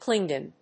/ˈklɪŋɒn(米国英語), ˈklɪˌŋgɔ:n(英国英語)/